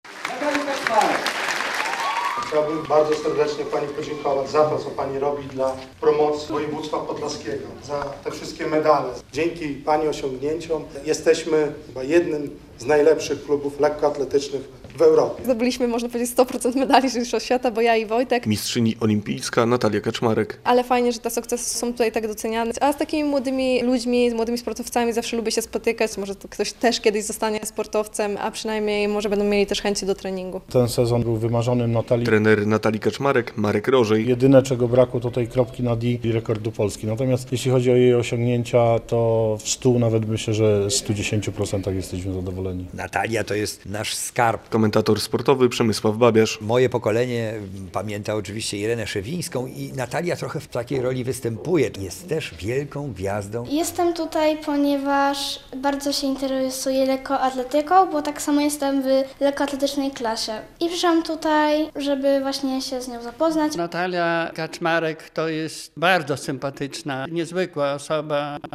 Spotkanie z Natalią Kaczmarek - relacja